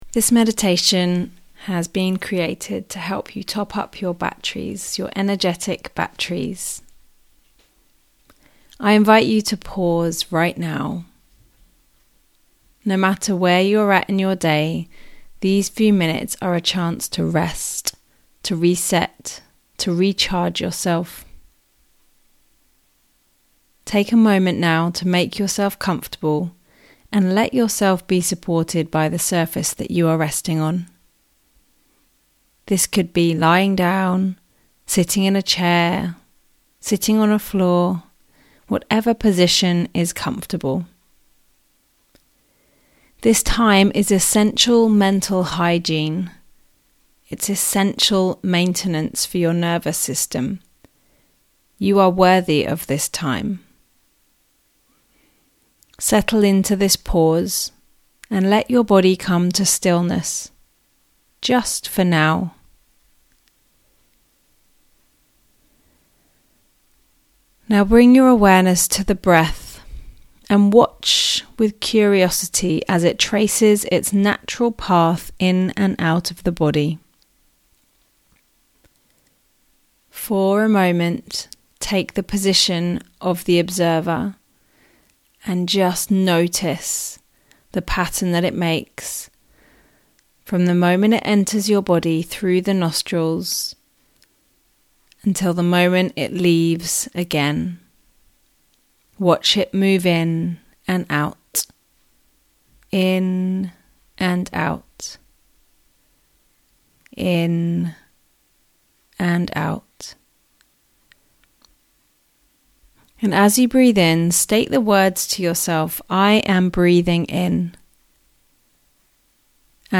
Breath Meditation (6 minute audio)